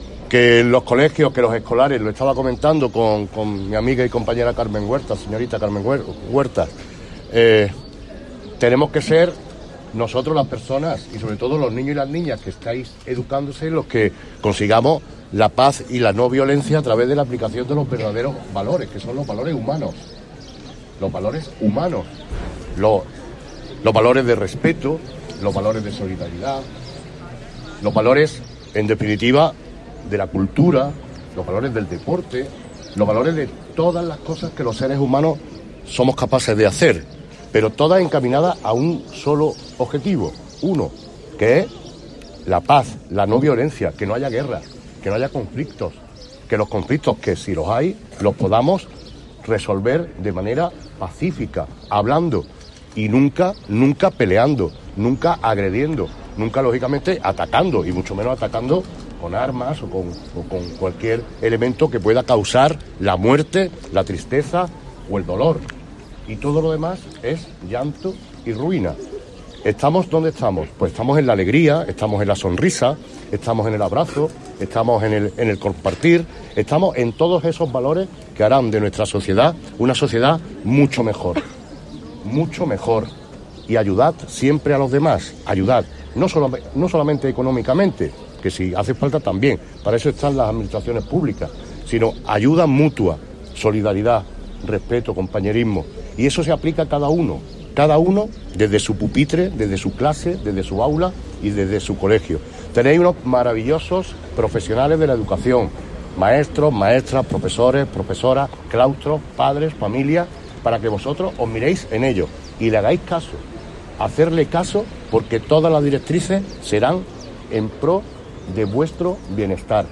El alcalde Manolo Barón y la concejal Sara Ríos asisten a la conmemoración en Antequera del Día Escolar de la No Violencia y la Paz
Cortes de voz